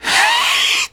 SOFT ATTAC.wav